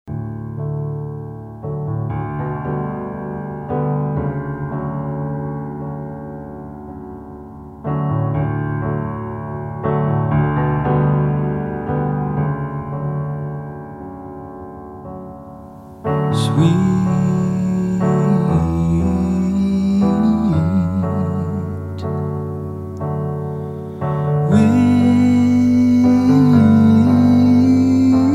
Pure analogue
old school R&B and Folk to Art Rock and Ambient music